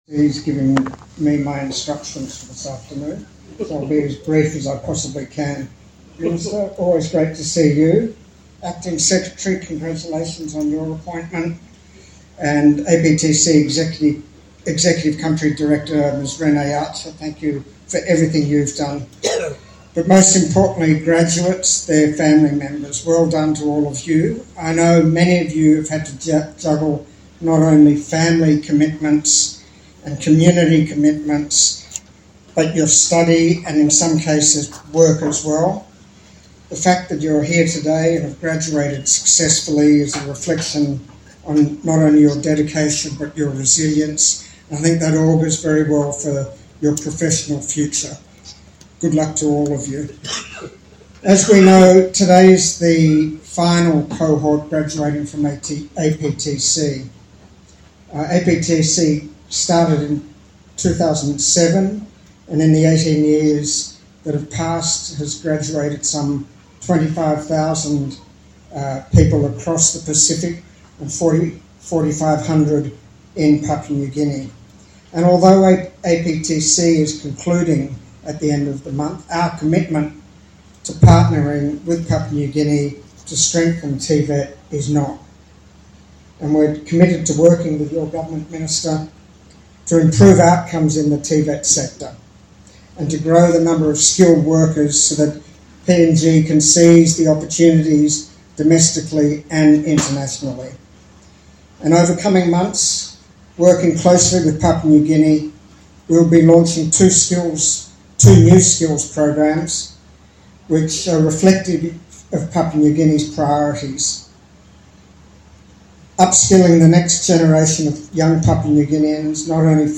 Slideshow and speech recordings of the Australia Pacific Training Coalition (APTC) in Port Moresby on March 7.
Closing Remarks by Australian High Commissioner John Feakes